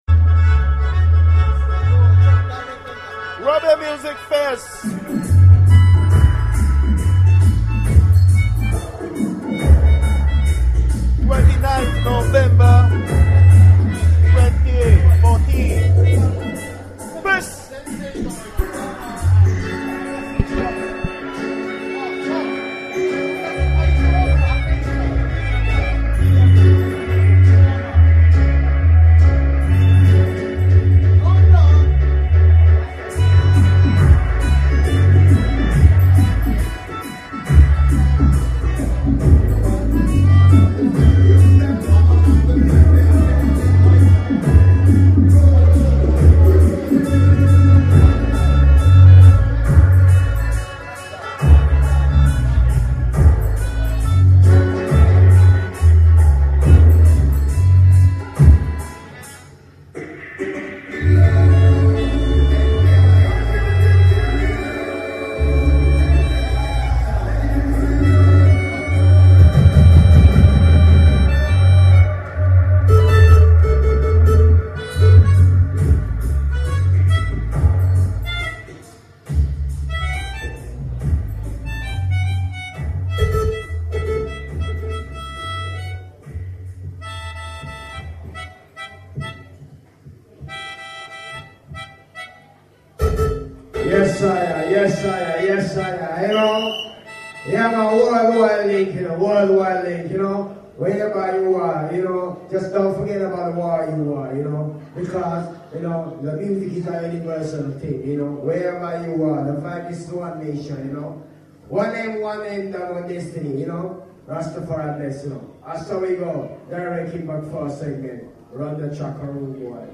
Live Recording from Direct Impact SoundSystem on 29th Nov at Illuminoid East Japan,Hannocity